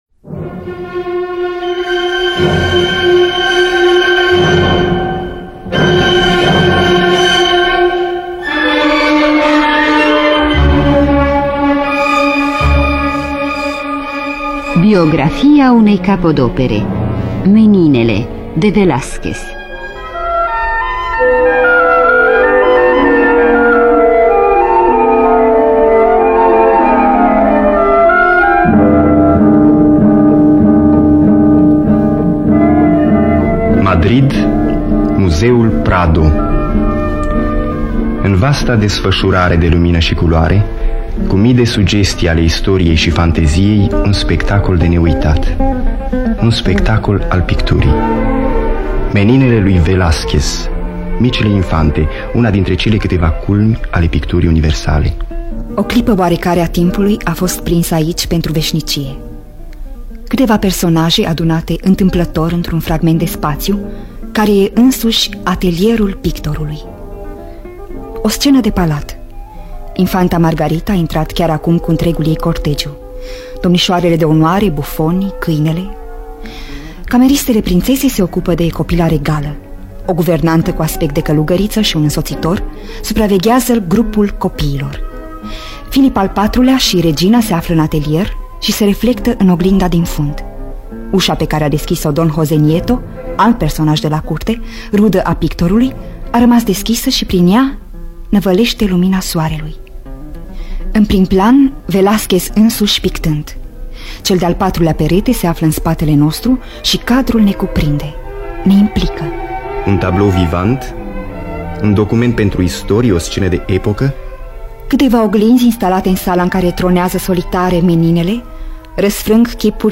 Scenariu radiofonic de Catinca Muscan.